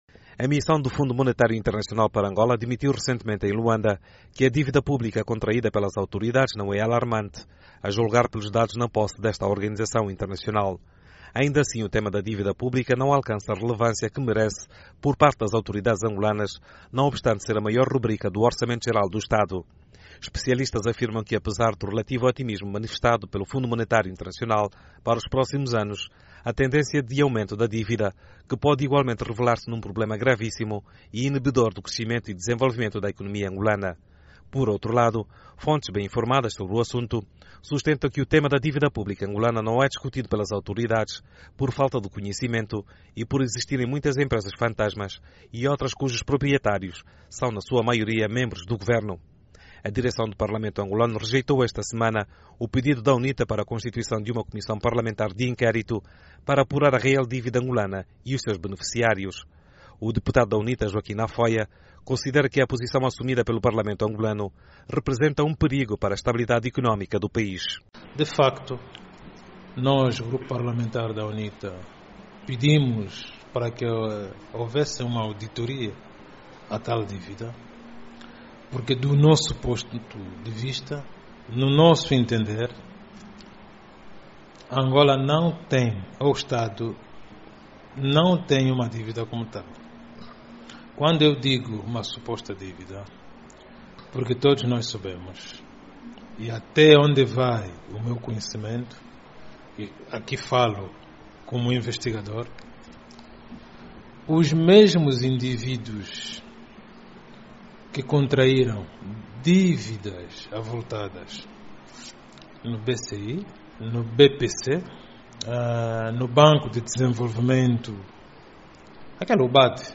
O governo angolano continua a declinar a realização de uma auditoria à dívida pública angolana, contra a vontade da classe empresarial e dos partidos políticos na oposição. Para falar sobre o assunto, ouvimos o Deputado da UNITA, Joaquim Nafoia